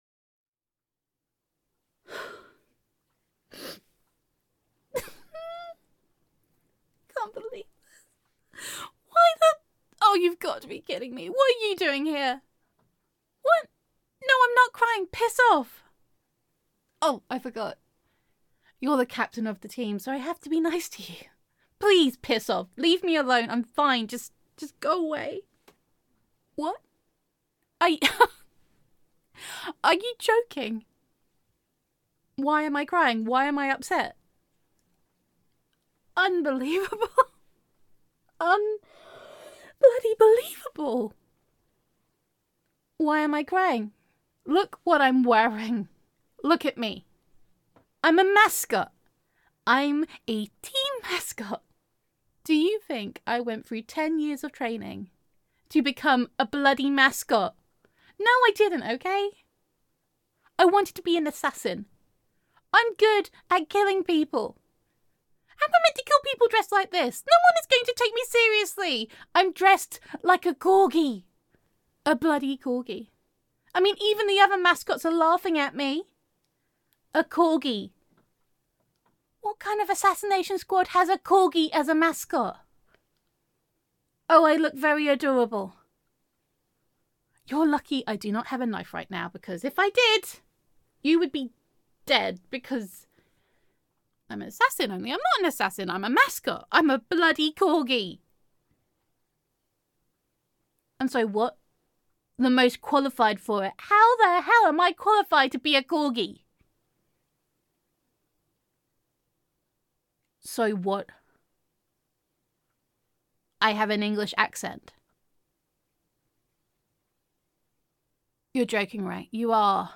[F4A] Death by Corgi [Corgi Mascot][I Am Not Adorable][Assassination Squad][Tricky Tricky][the Superior Assassin][Gender Neutral][Even Assassin Teams Need a Mascot]